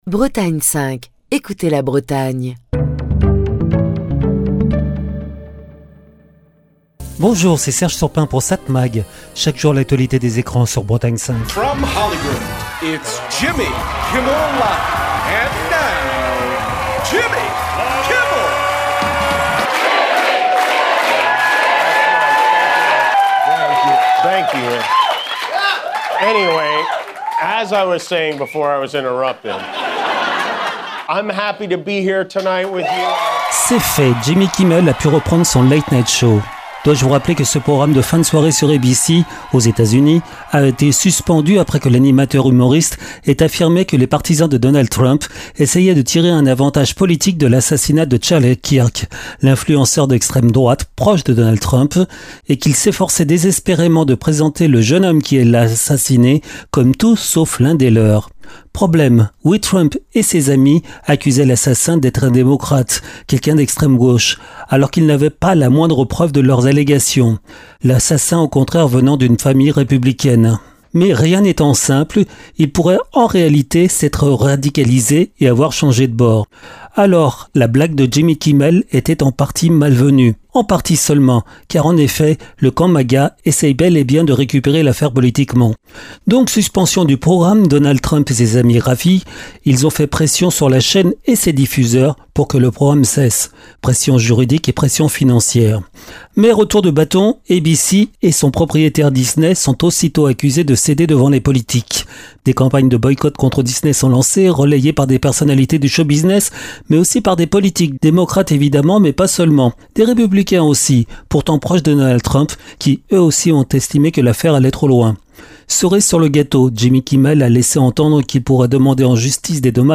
Chronique du 25 septembre 2025.